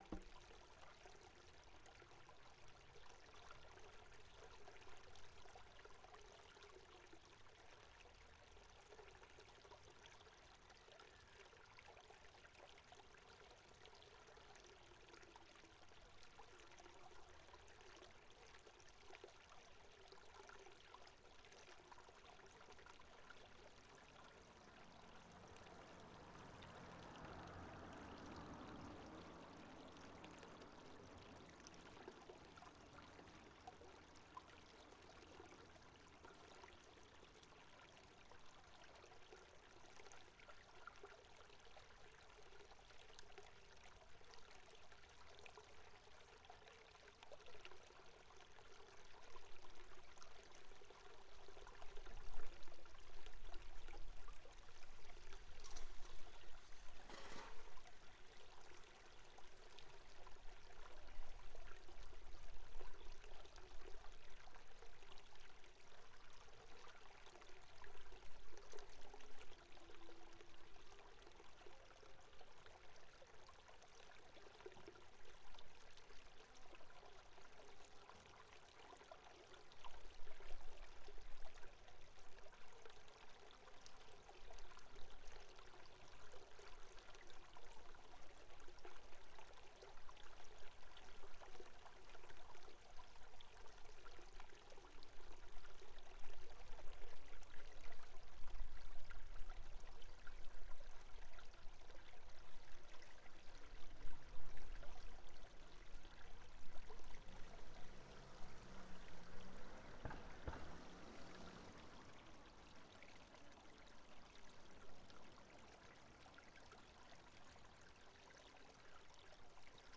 レコーダーはDR-100、内蔵マイク
中里橋から少し離れた所と近くで録音。
内蔵マイク 指向性ステレオマイク